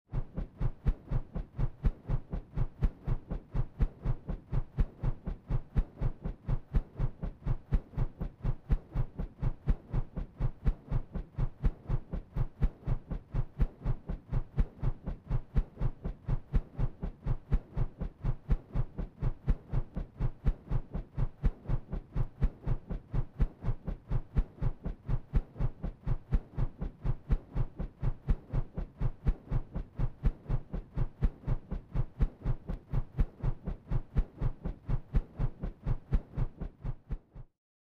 Звуки бытовой техники
Вращение лопастей вентилятора, разрезание воздуха